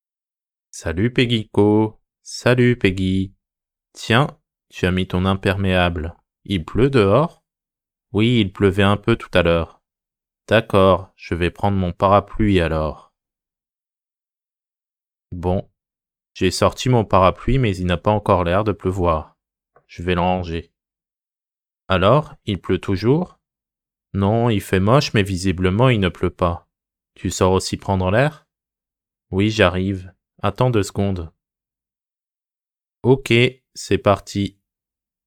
音声：ゆっくり
音声：通常速度